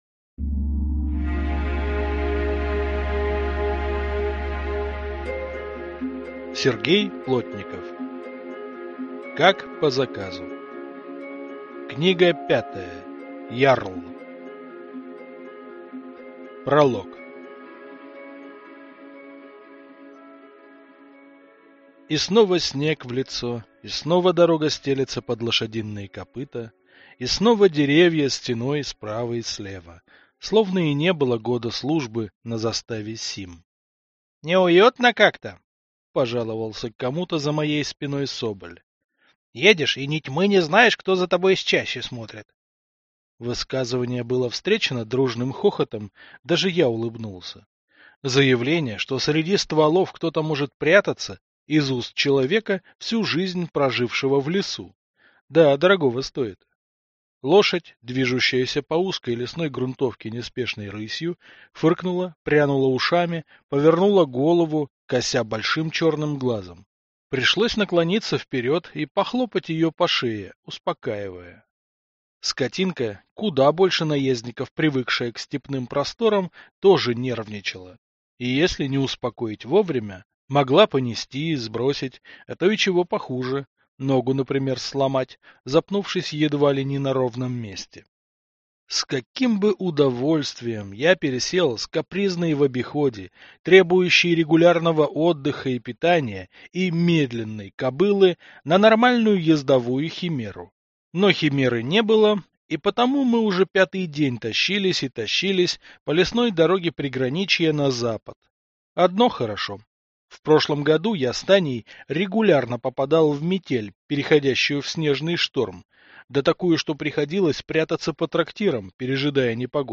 Аудиокнига Ярл | Библиотека аудиокниг